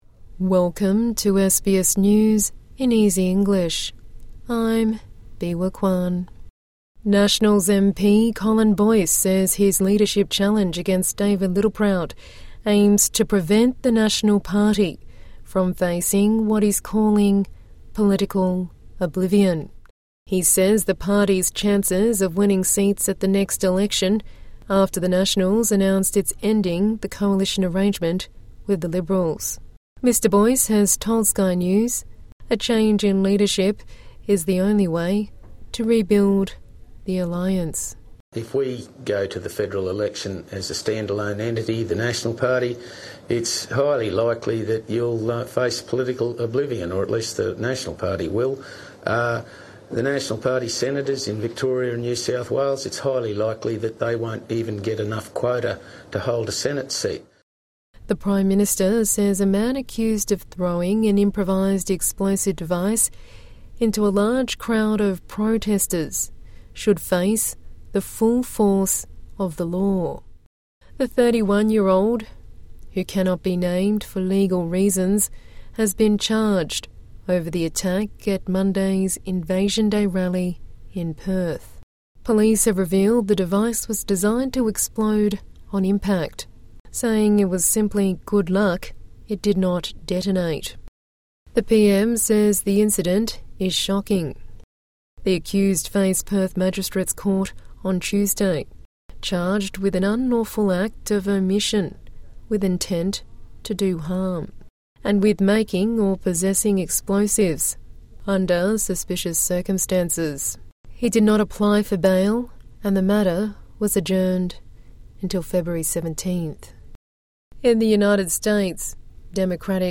A daily 5-minute news bulletin for English learners and people with a disability.